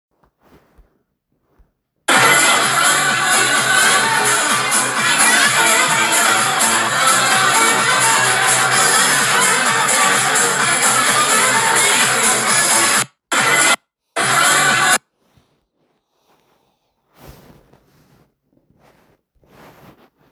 - Muzyka elektroniczna